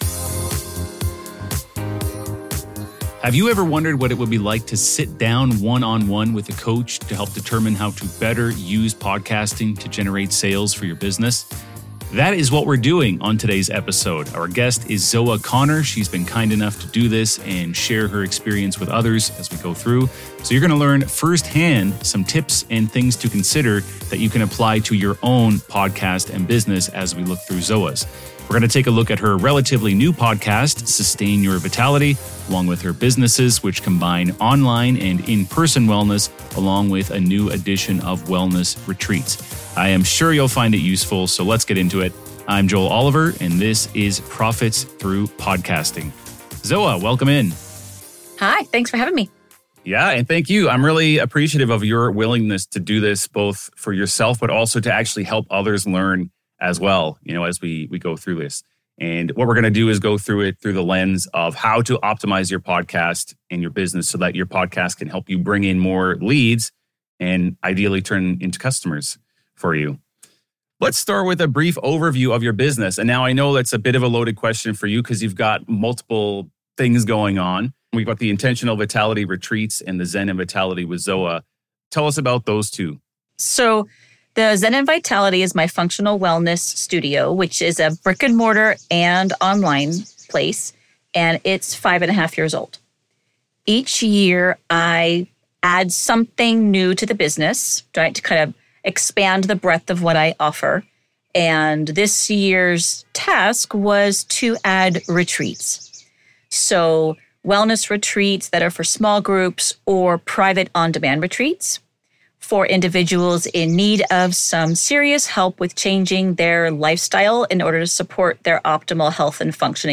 Coaching Session!